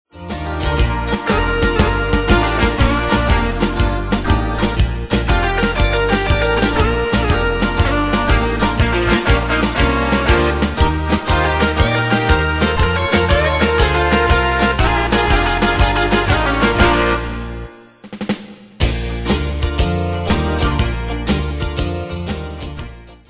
Style: PopAnglo Ane/Year: 1994 Tempo: 120 Durée/Time: 2.24
Danse/Dance: TwoSteps Cat Id.
Pro Backing Tracks